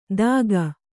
♪ dāga